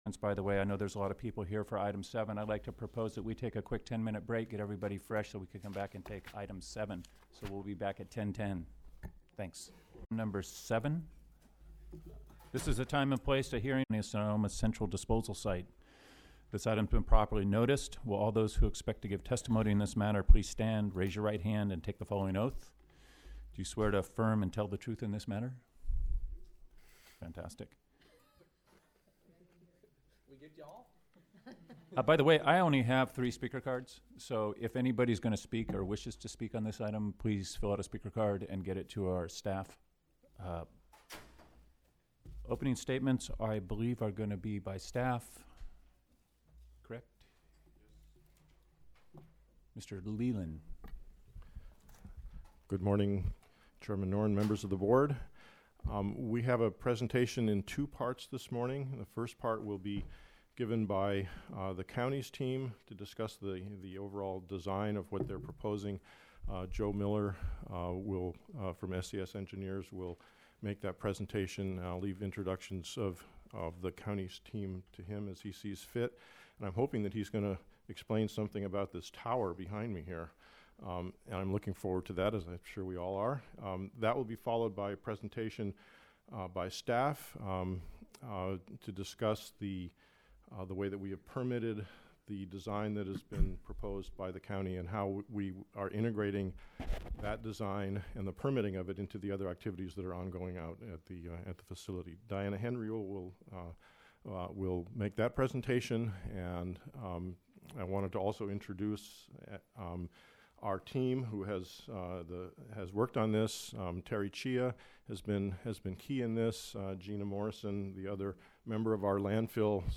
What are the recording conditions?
03/2013 Baord Meeting | California Northcoast Regional Water Quality Control Board